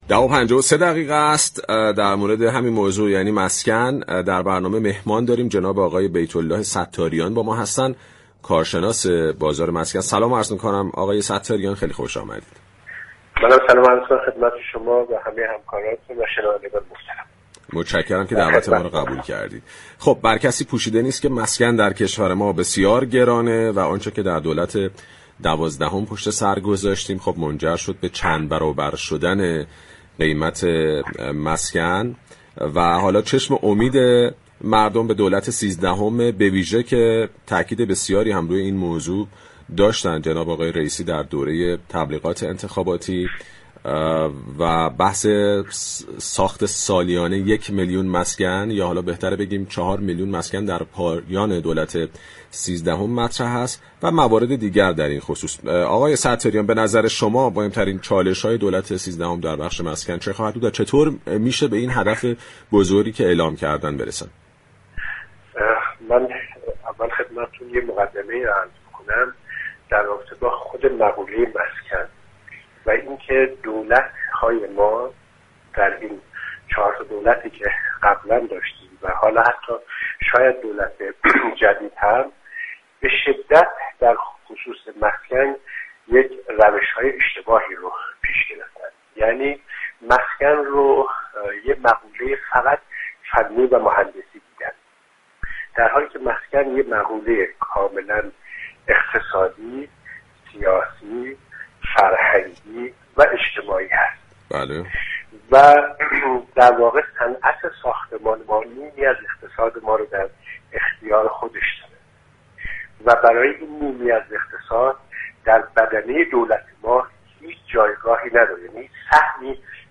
در گفتگو با بازار تهران رادیو تهران